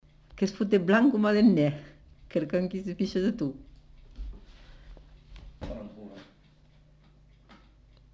Genre : forme brève
Effectif : 1
Type de voix : voix de femme
Production du son : récité
Classification : locution populaire
Ecouter-voir : archives sonores en ligne